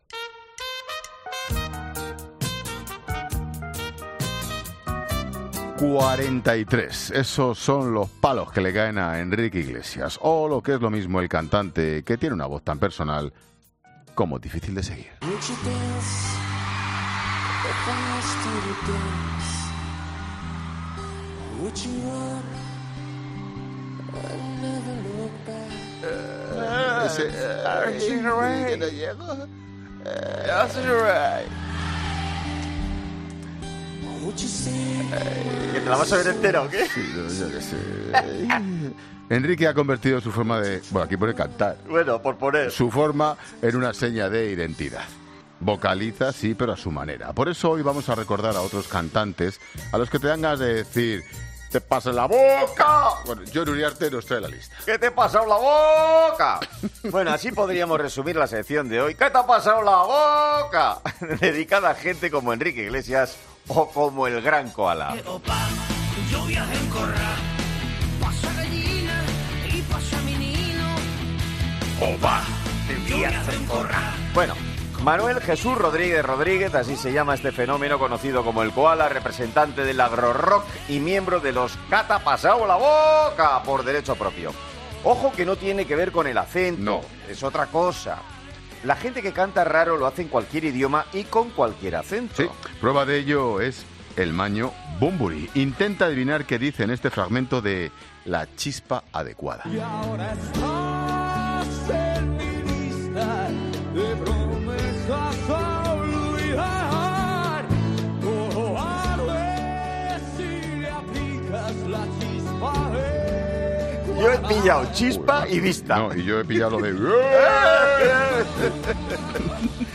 es un magazine de tarde que se emite en COPE